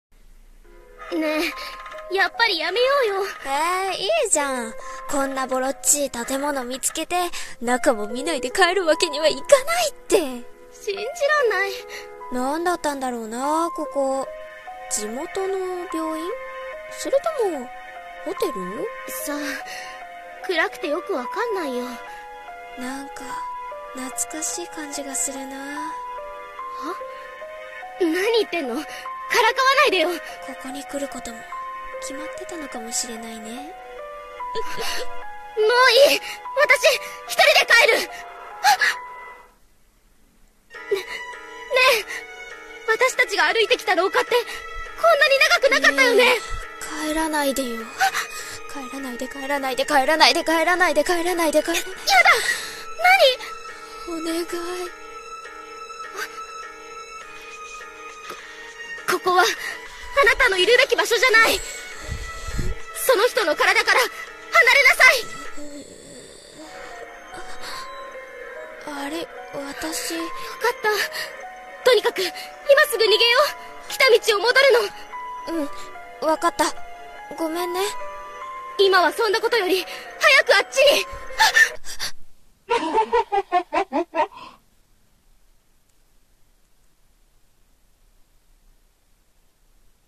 【コラボ声劇】憑依の館